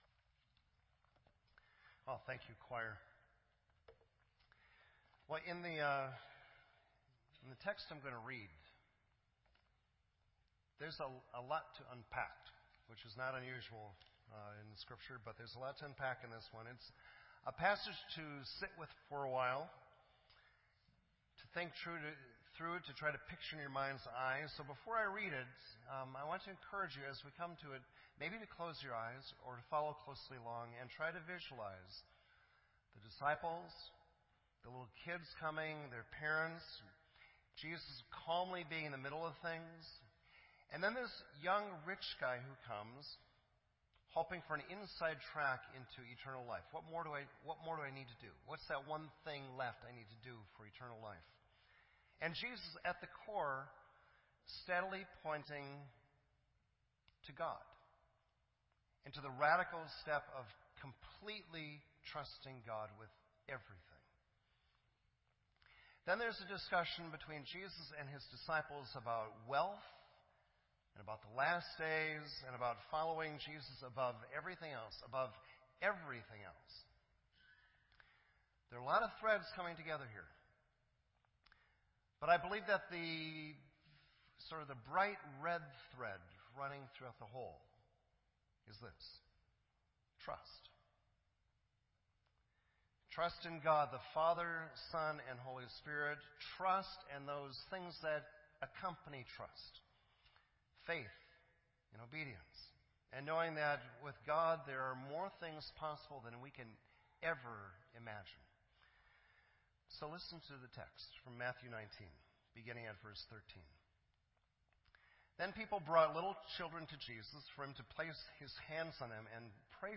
This entry was posted in Sermon Audio on April 3